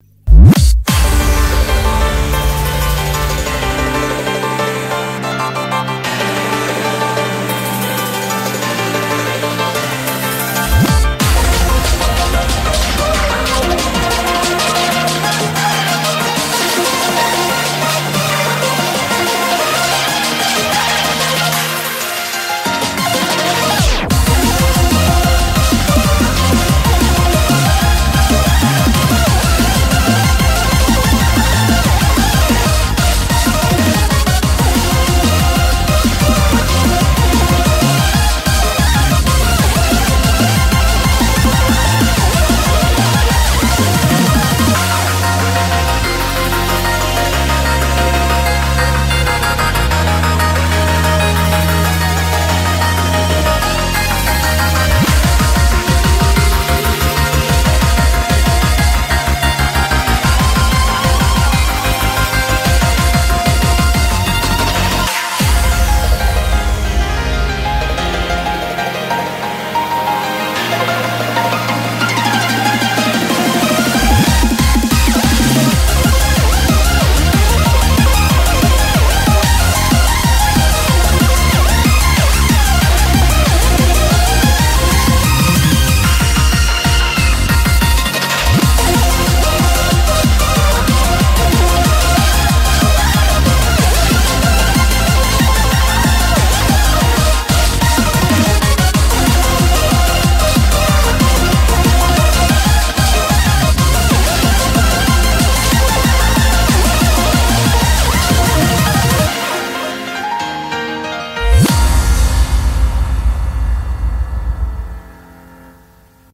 BPM93-186
Audio QualityLine Out